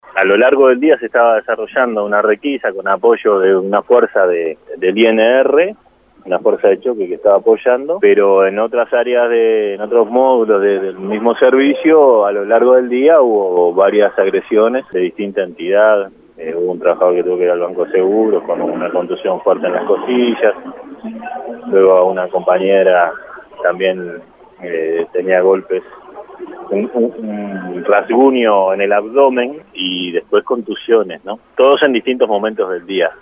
Descargar Audio no soportado Por otro lado, el director por la oposición en el INAU, Dardo Rodríguez, dijo a 810 Vivo que la licencia que solicitó la directora Fulco fue pedida antes de asumir .